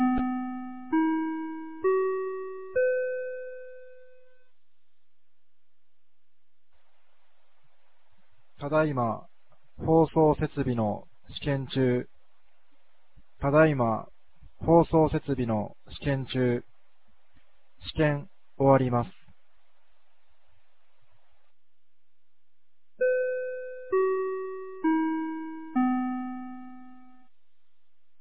2025年08月02日 16時04分に、由良町から全地区へ放送がありました。